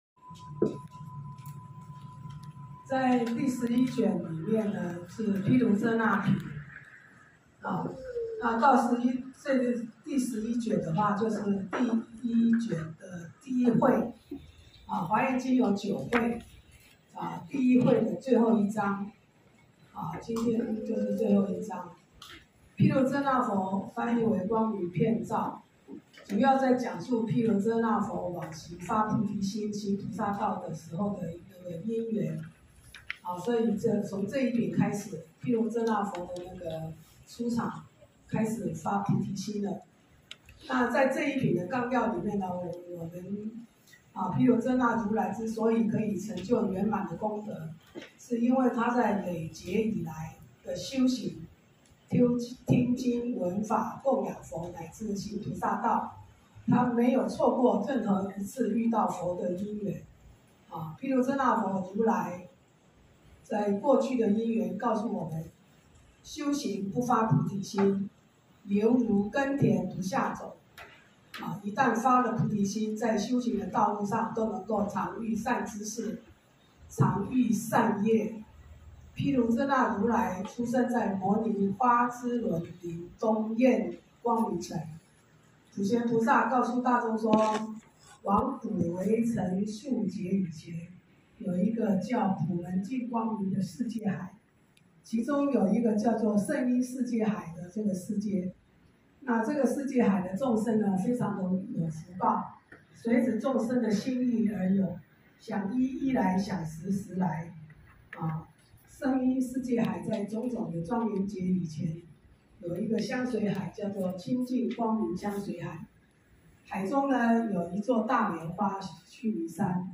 週六共修---讀誦華嚴經第11卷